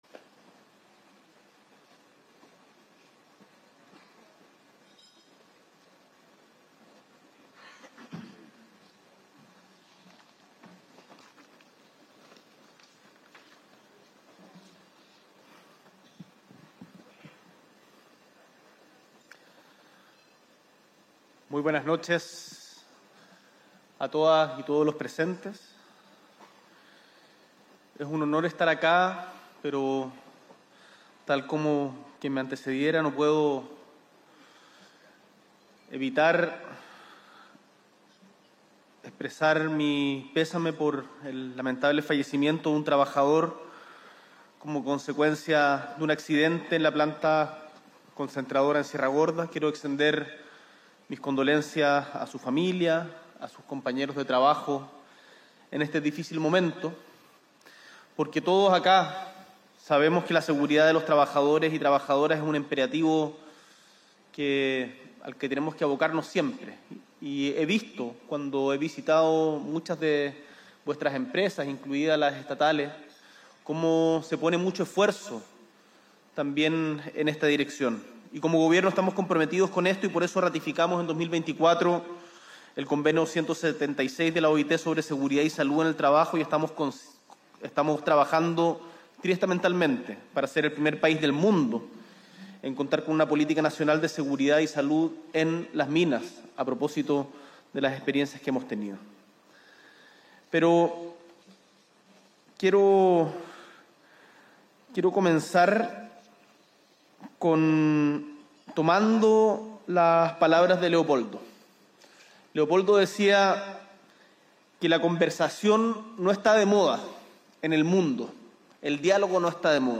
S.E. el Presidente de la República, Gabriel Boric Font, participa de la cena CESCO Week.